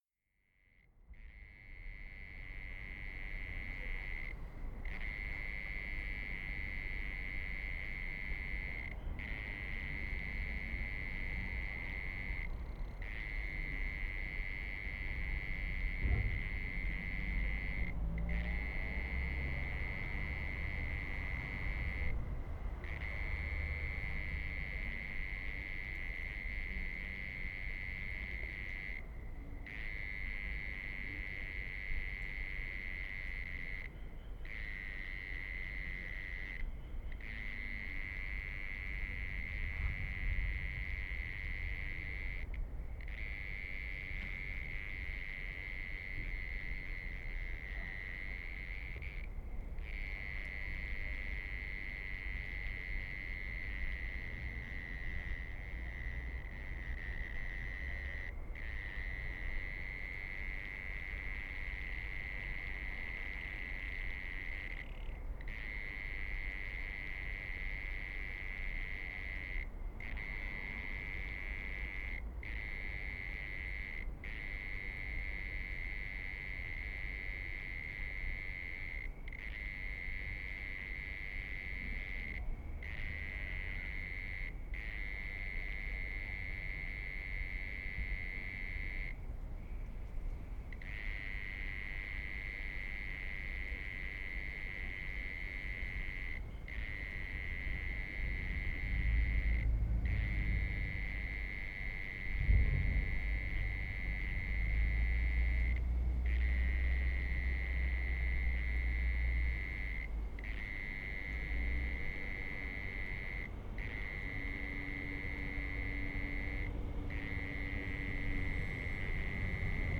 ZOOM F6, Telinga Modular, Clippy XLR EM272Z1, 2023年4月14日 山形県鶴岡市
美しいヒガラのさえずり。おそらく同じ一羽を追っていて、連続した３つの録音を繋いでいます。場所を変え全く違うタイプの声を聞かせてくれました。最後はキツツキが近くにとまって威嚇の声をあげています。
期待通りの音に嬉しくなって、早速アップしたというわけ。